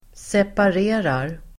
Uttal: [separ'e:rar]